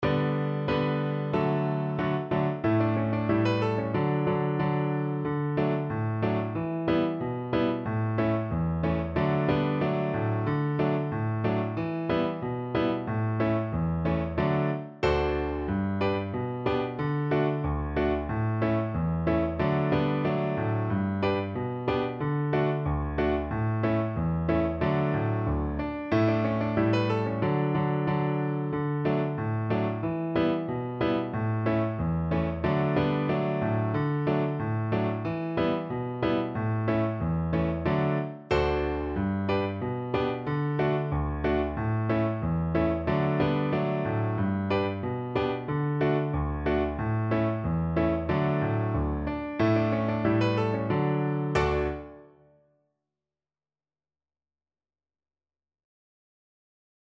Jolly =c.92